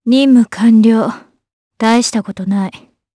Ripine-Vox_Victory_jp.wav